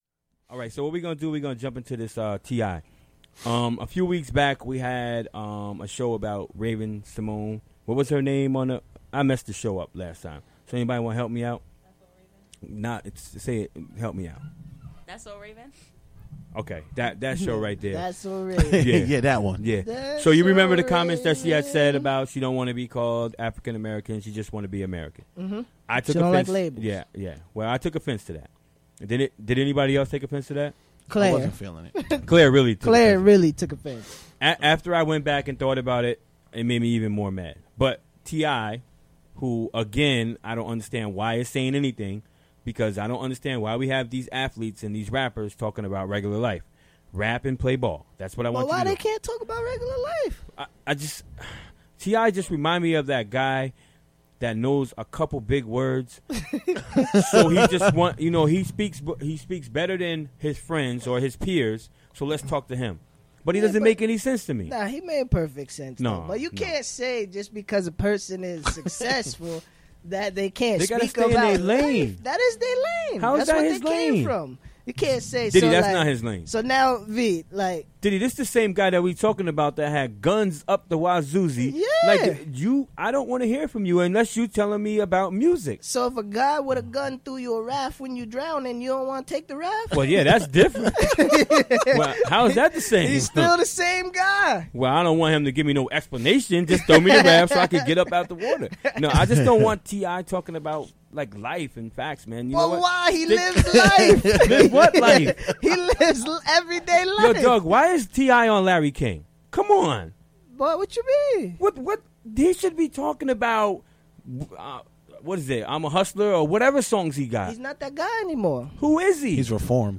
Discussion about racial identity, language and labeling in response to comments made by rapper T.I. about being called "African American." (25:32) (Audio)